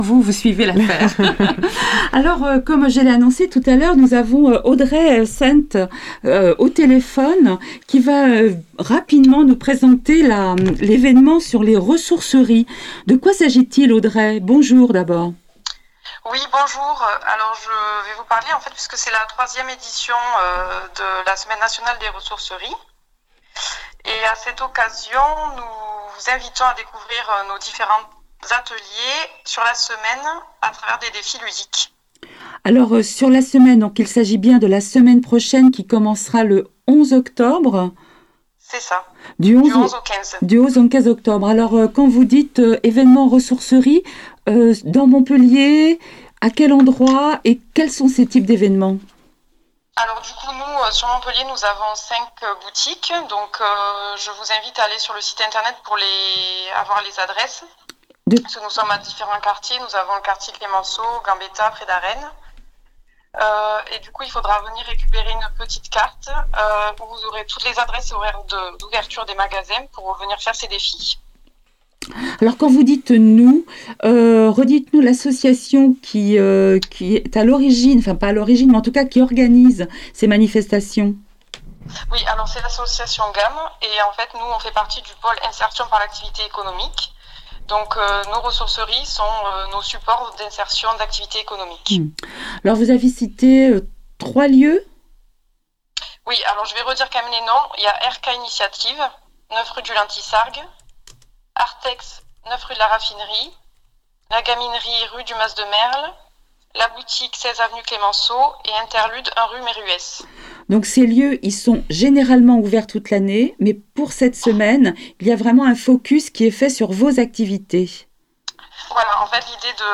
On parle du Ressour’circuit sur Radio FM+
Interview-sur-les-Ressourceries-Gammes-sur-FM.mp3